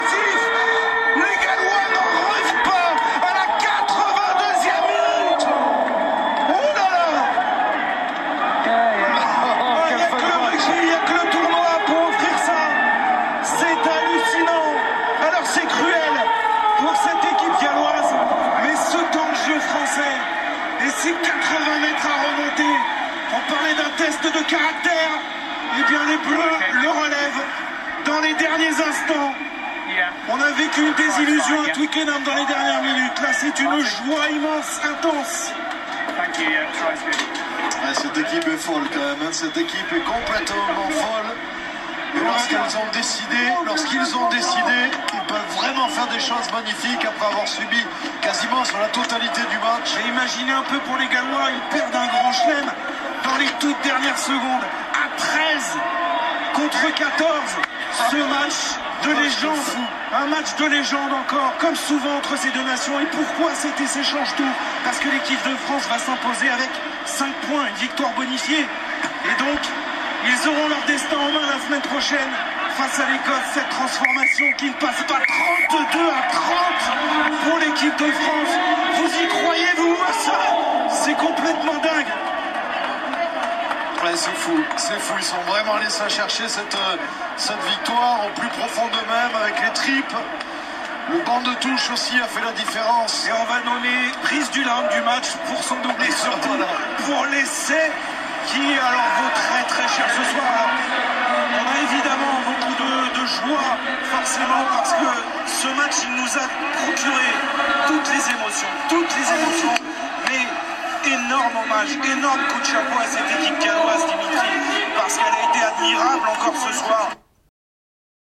Pour commencer, plongée dans l’ambiance de la toute fin du match France – Pays de Galles. Il n’y avait pas de spectateurs dans le stade mais les journalistes sportifs se sont laissé emporter en direct par leurs émotions, entraînant derrière eux les spectateurs installés devant leur écran de télévision. Prêts à essayer de comprendre ce qu’ils criaient ?